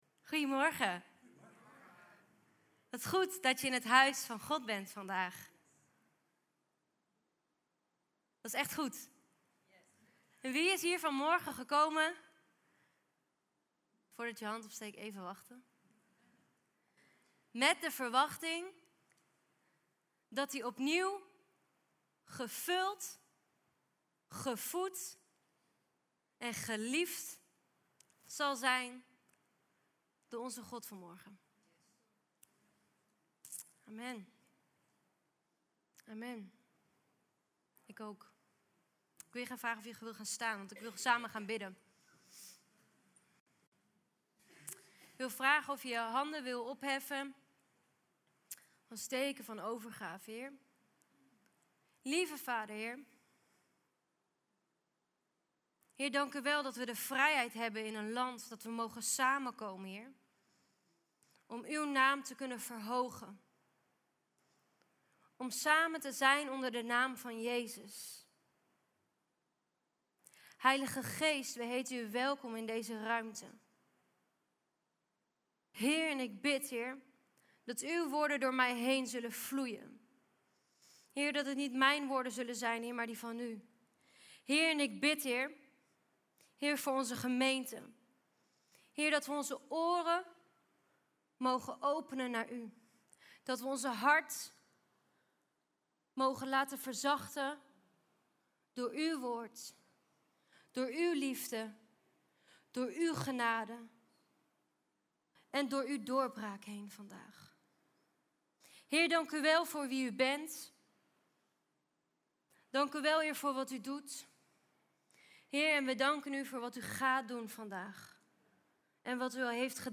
Preek terug luisteren van LEEF! Doetinchem | LEEF!